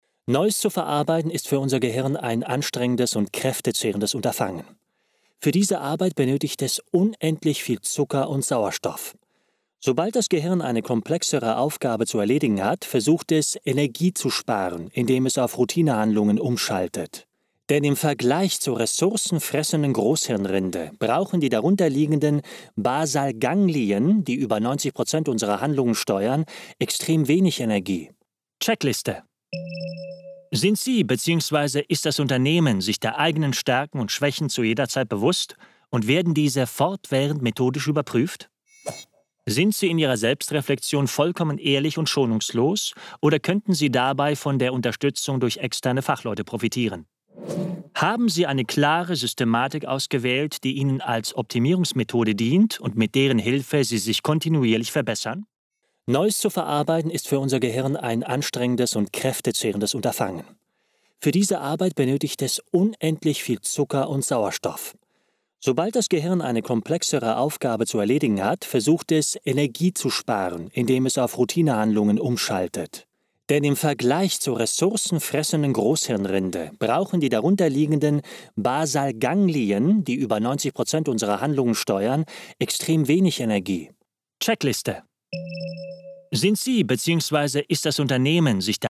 Souverän, Seriös, Selbstbewusst, Markant, Glaubwürdig, Vielseitig & Wandelbar, Sympathisch, mit Wiedererkennungswert
Kein Dialekt
Sprechprobe: Sonstiges (Muttersprache):
Believable, Versatile, Trusted, Characters, Young, Actor, Casual